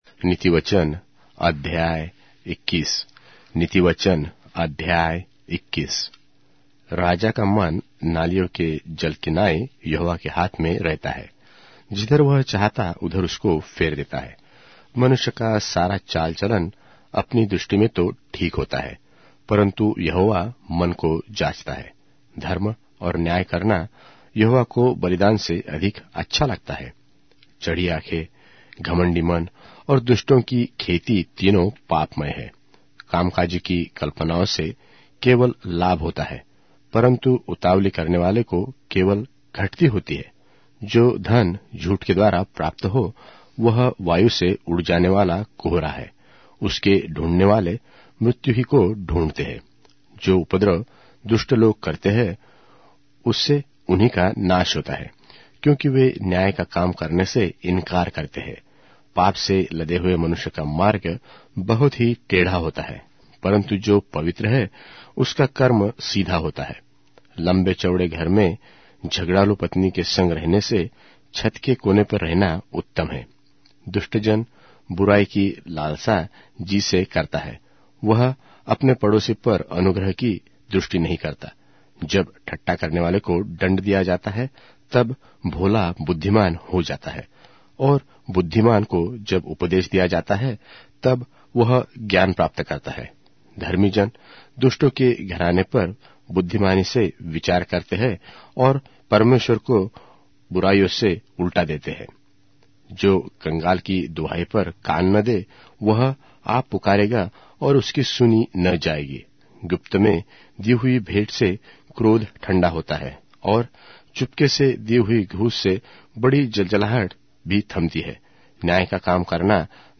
Hindi Audio Bible - Proverbs 10 in Lxxrp bible version